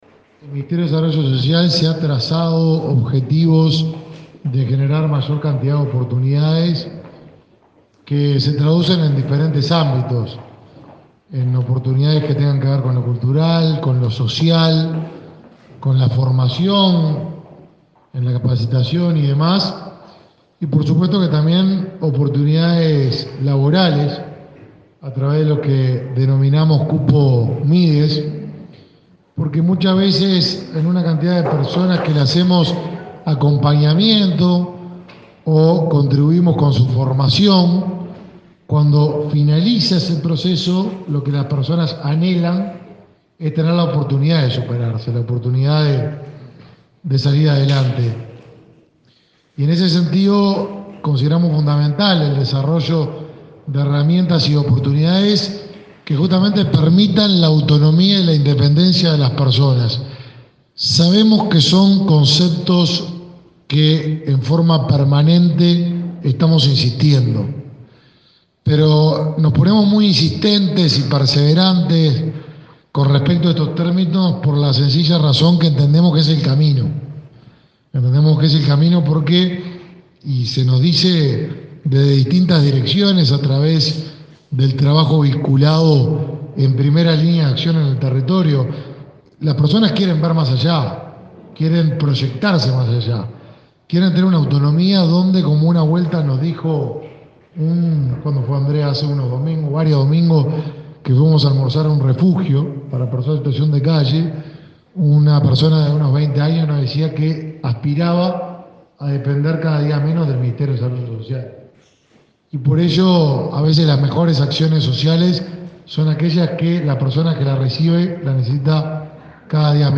Palabras del ministro de Desarrollo Social, Martín Lema